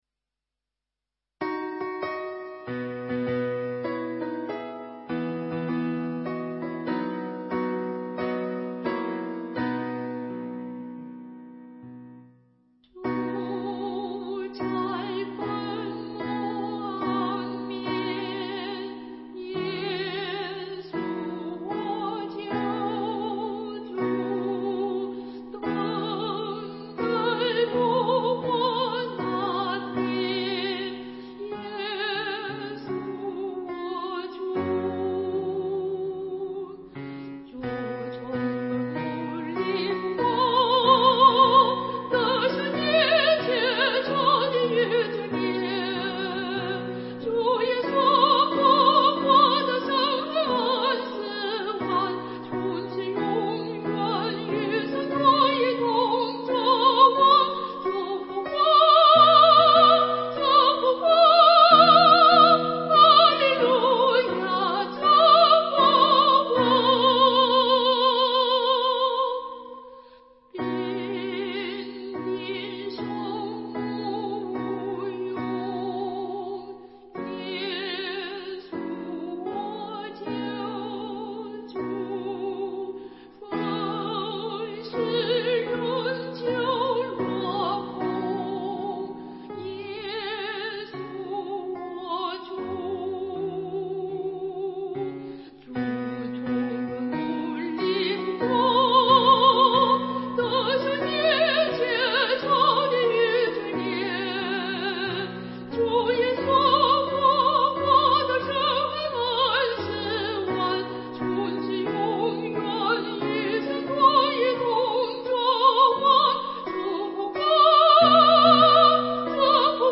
伴奏
当我们唱副歌时，却与前面的词句有强烈的对比，旋律豁然开朗，充满了活跃、喜乐的气氛，节拍加速而强劲高昂。